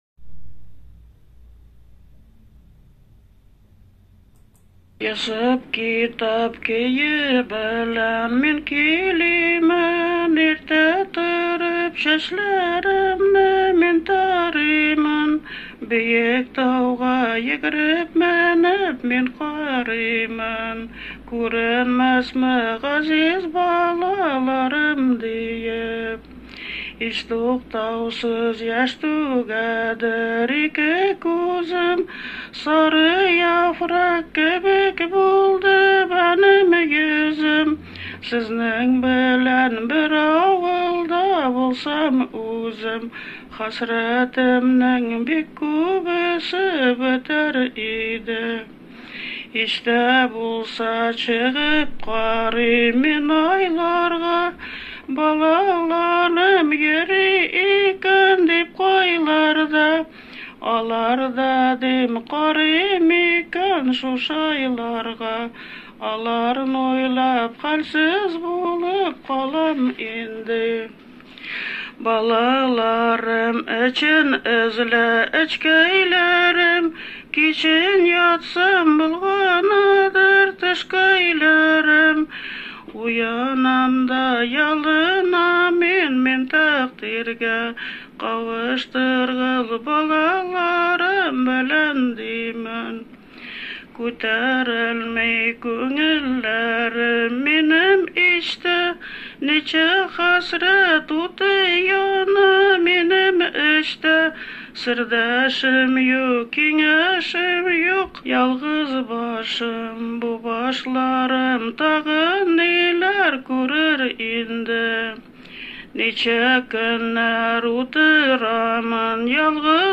ПЕСЕННАЯ ТРАДИЦИЯ ОБСКИХ ЧАТОВ ИЗ НОВОСИБИРСКОЙ ОБЛАСТИ ВОШЛА В ПРОЕКТ «АНТОЛОГИЯ НАРОДНОЙ КУЛЬТУРЫ»